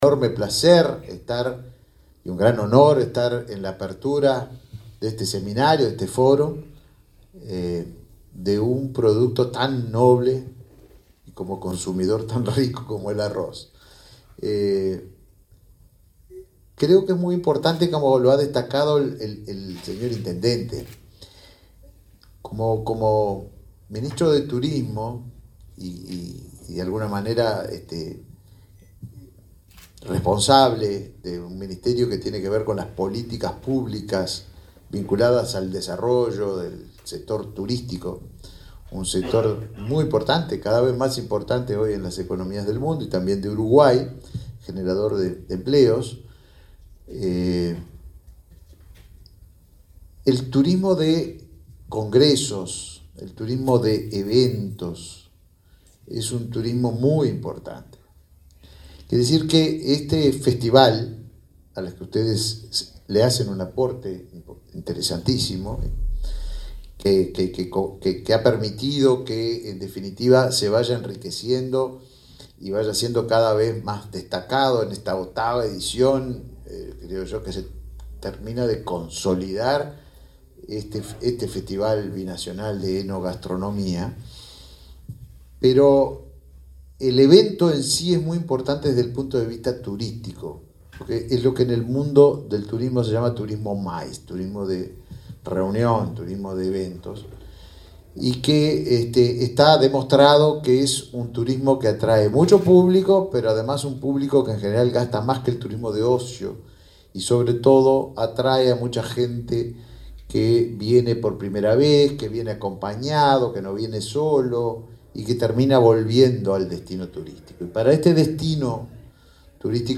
Palabras del ministro Tabaré Viera en el Foro Binacional del Arroz
El ministro de Turismo, Tabaré Viera, participó, este viernes 4 en Rivera, del Foro Binacional del Arroz Rivera-livramento.